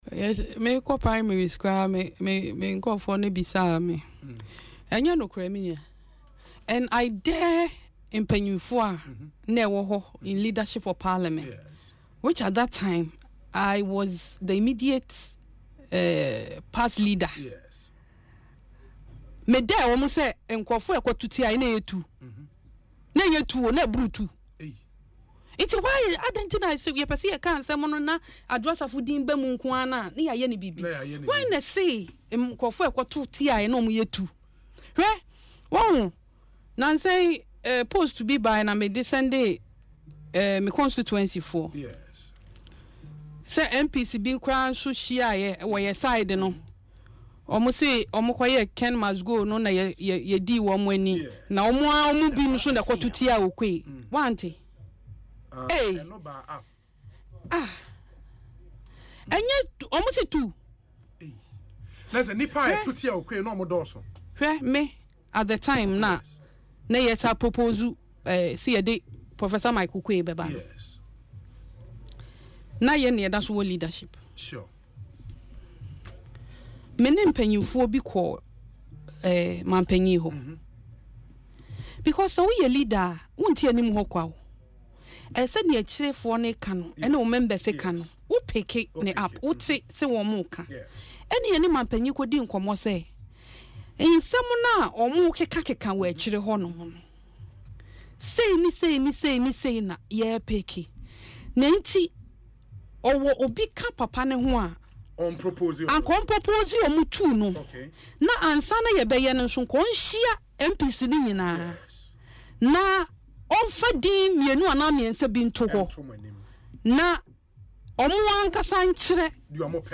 Speaking in an interview on Asempa FM’s Ekosii Sen, Adwoa Safo explained that Ahenkorah’s controversial decision to snatch and chew some of the ballot papers helped prevent a major embarrassment for the NPP.